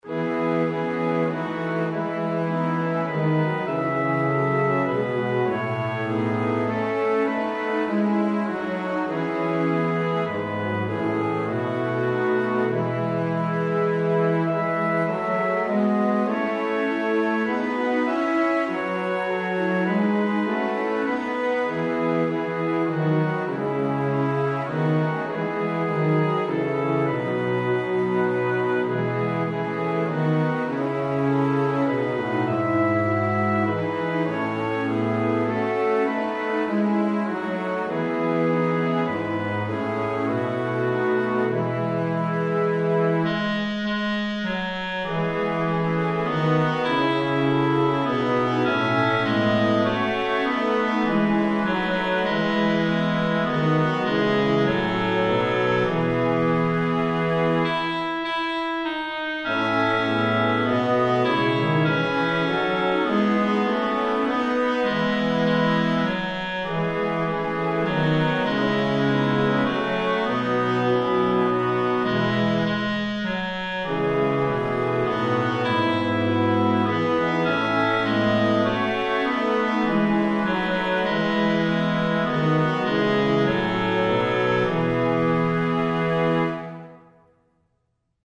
FullScore